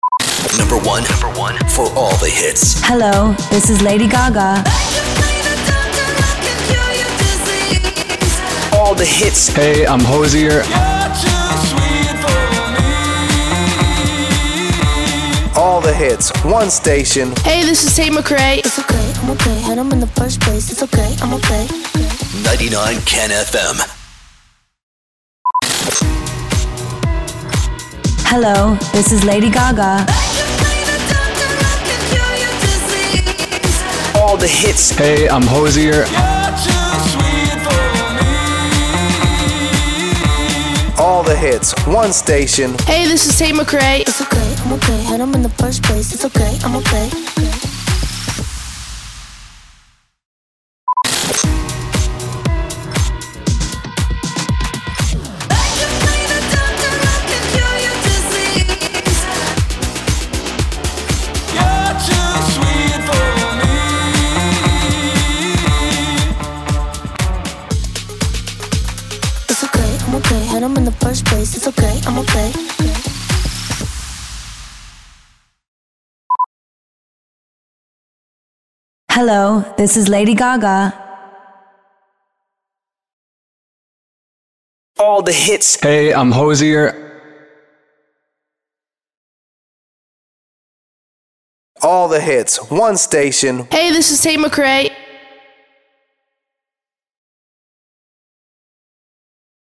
659 – SWEEPER – BEATMIX PROMO
659-SWEEPER-BEATMIX-PROMO.mp3